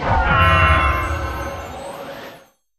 Cri de Glaivodo dans Pokémon HOME.